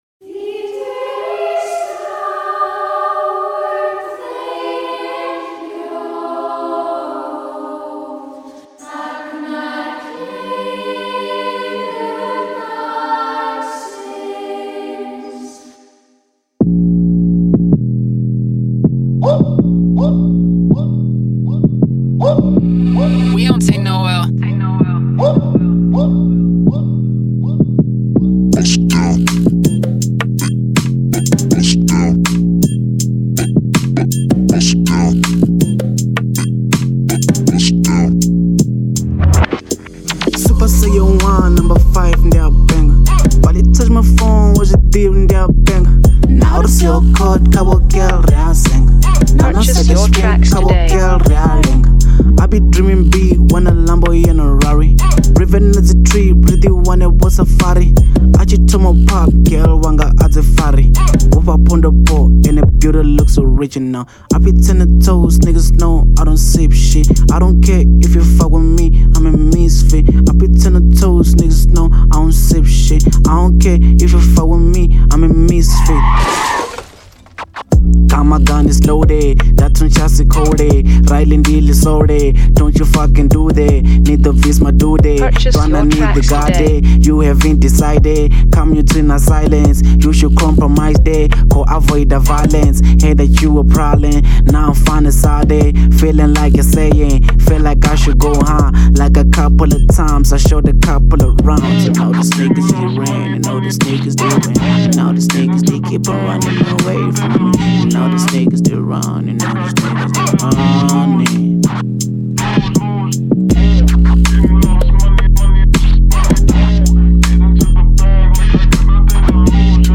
04:10 Genre : Venrap Size